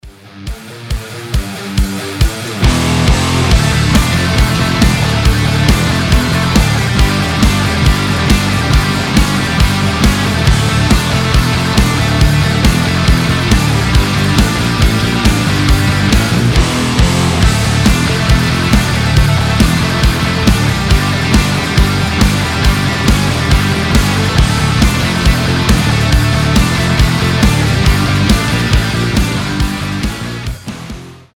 • Качество: 320, Stereo
громкие
мощные
без слов
Alternative Metal
тяжелый рок